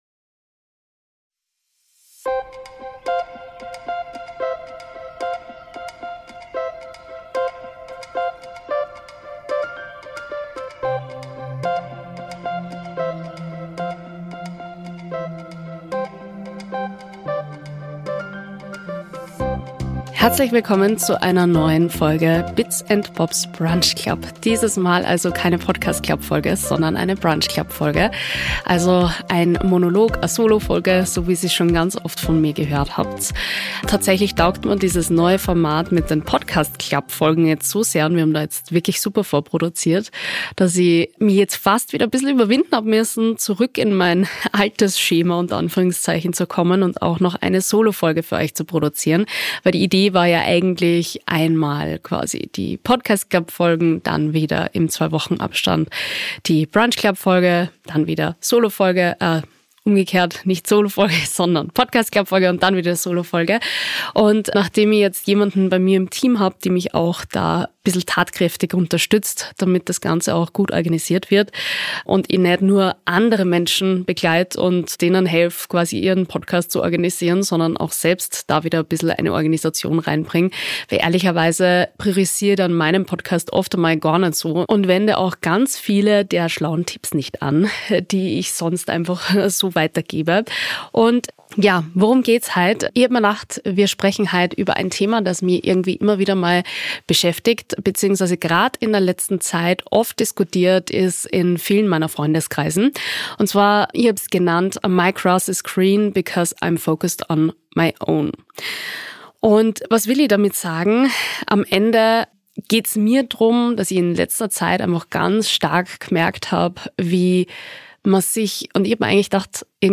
Ich spreche in dieser Solofolge darüber, warum wir dazu tendieren, ständig nach links und rechts zu schauen, wieso Social Media diesen Effekt noch verstärkt und was das eigentlich mit uns macht.